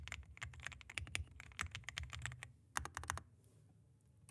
Xyran päämateriaali onkin muovia, muttei silti rämise, saati kilise.
Kytkintyyppi: Lineaarinen
Hiljennetyt ja esiliukastetut kytkimet tuovat pehmeän soinnun sormien alle, eikä nopeustesteissä jäänyt paljoakaan ammattilaispelikäyttöön tarkoitetusta huippunäppäimistöstä jälkeen.
Xyran kehutaan olevan todella hiljainen, mutta jää esim. Logitechin G515 Rapidin analogisista, sekä magneettisista kytkimistä selvästi kovaäänisemmäksi.